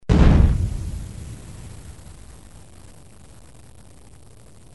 دانلود صدای بمب و موشک 15 از ساعد نیوز با لینک مستقیم و کیفیت بالا
جلوه های صوتی